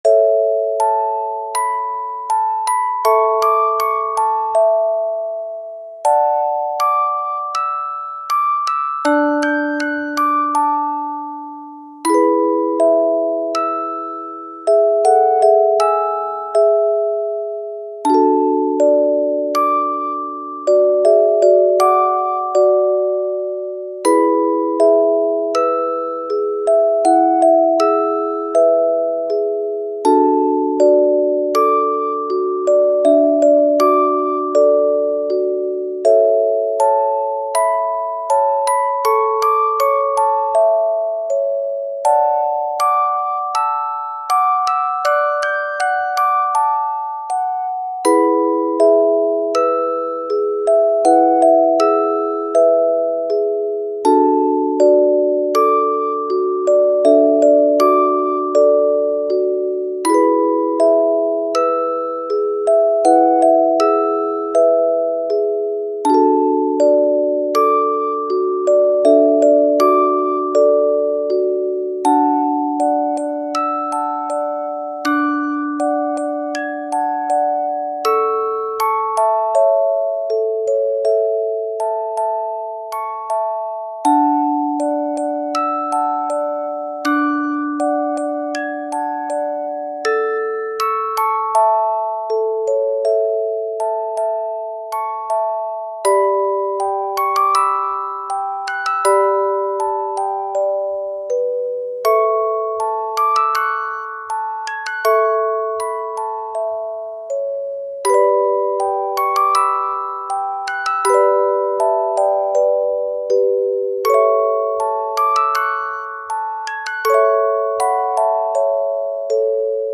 カテゴリ：オルゴール